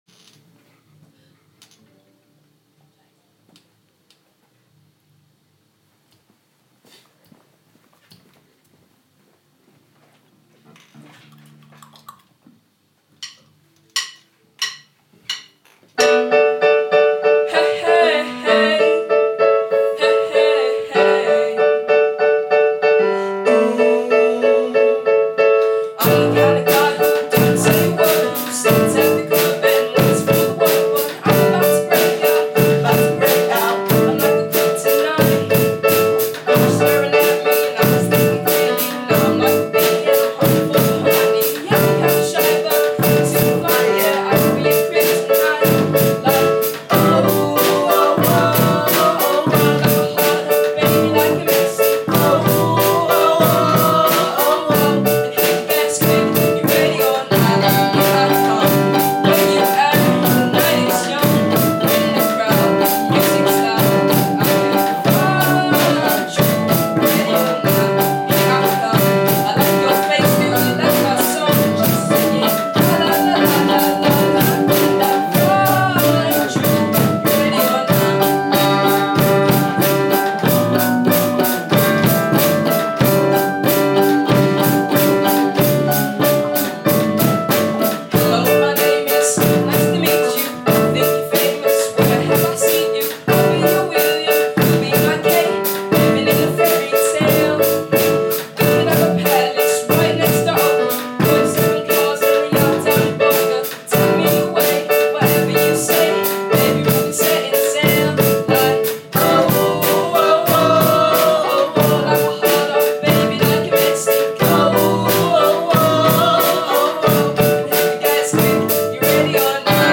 Vocals
drums
piano